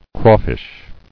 [craw·fish]